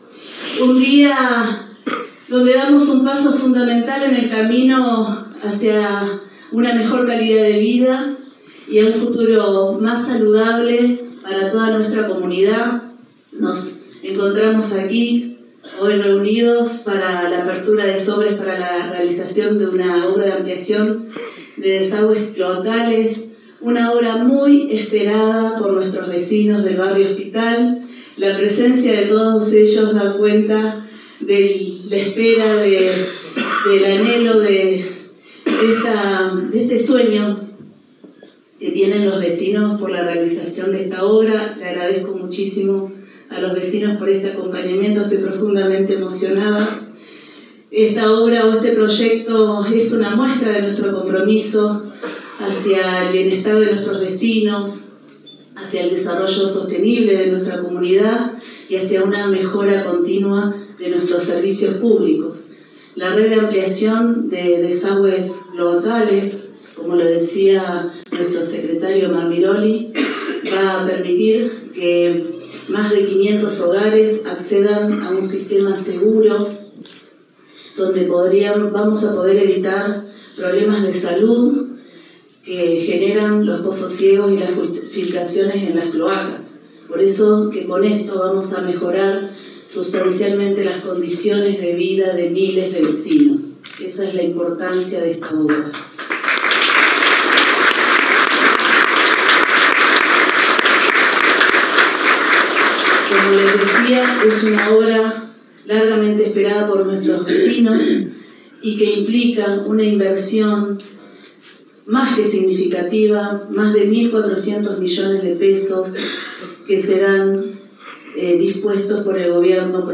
Intendente Municipal de la Ciudad de Vera – Paula Mitre